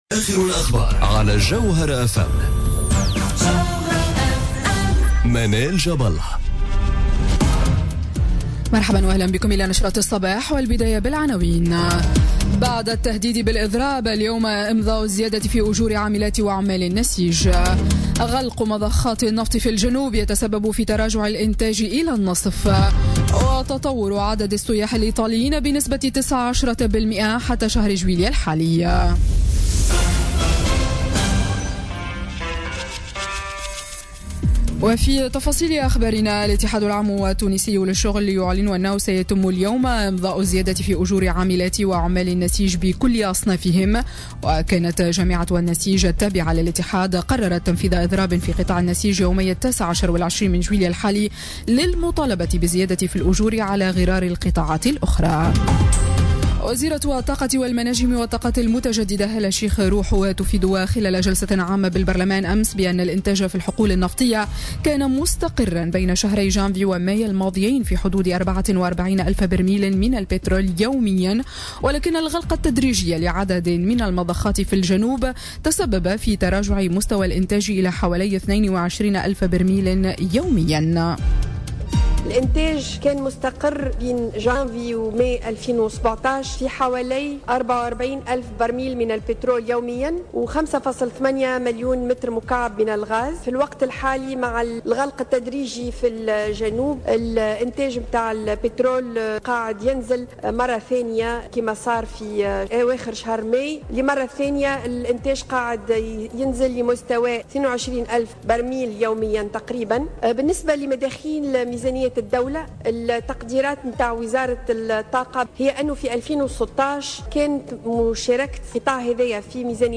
نشرة أخبار السابعة صباحا ليوم الثلاثاء 18 جويلية 2017